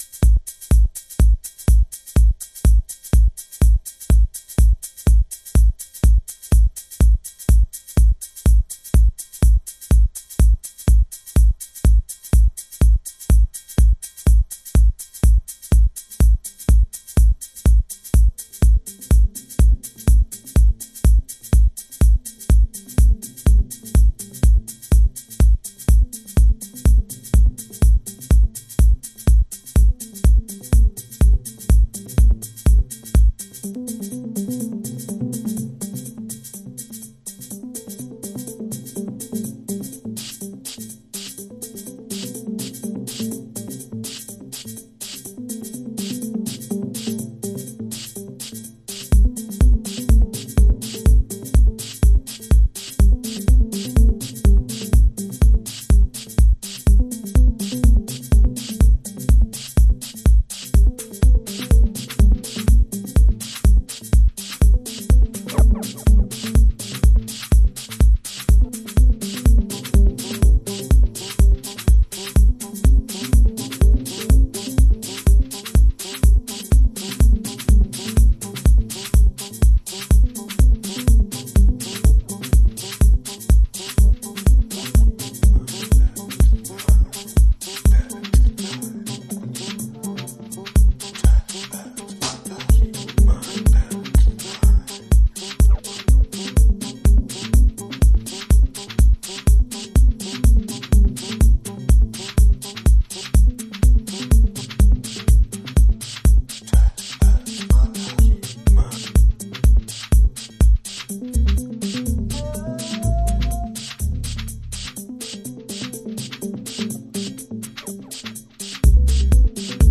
House / Techno
バランス感覚を狂わすミニマル